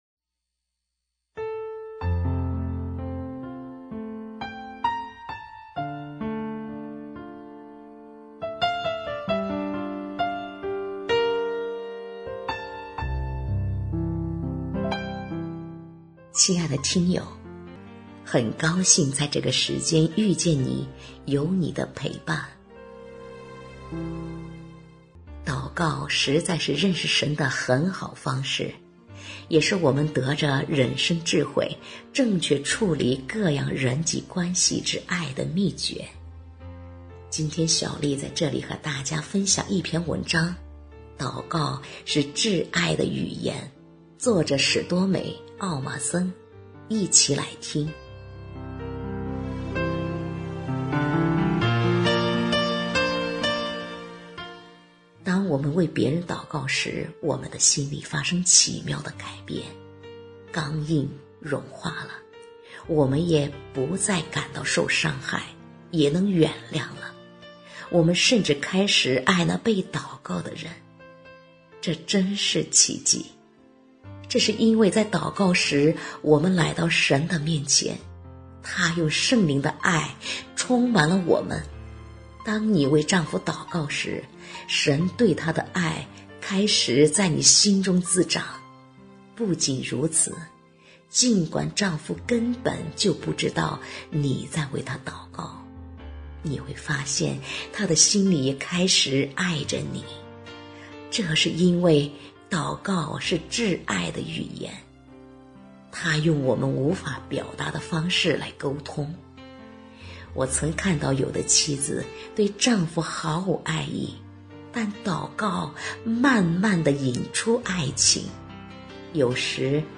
首页 > 有声书 > 婚姻家庭 > 单篇集锦 | 婚姻家庭 | 有声书 > 祷告是至爱的语言